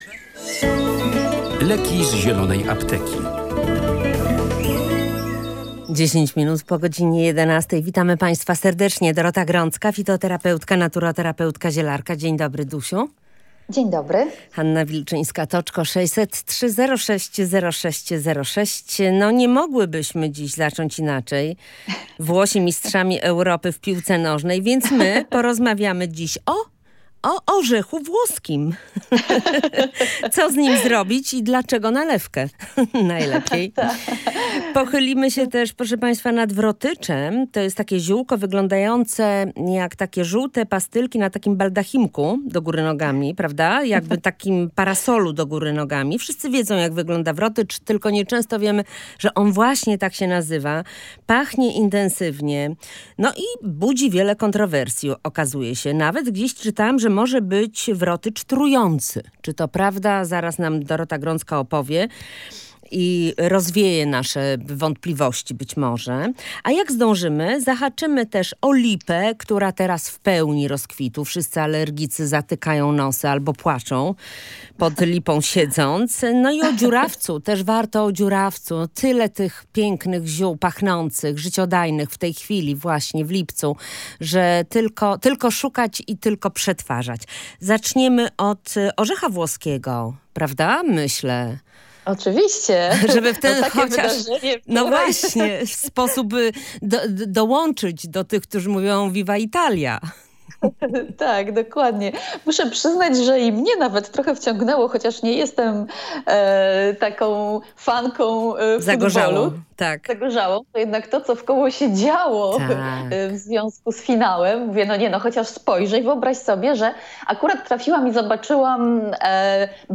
archiwum audycji